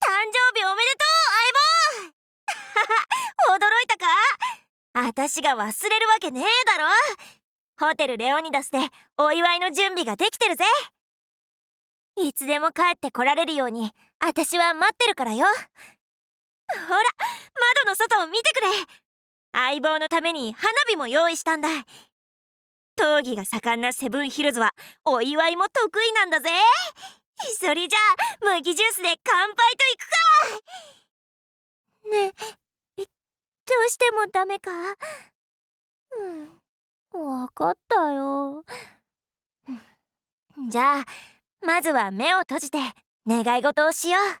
ルパの誕生日ボイス可愛すぎる😤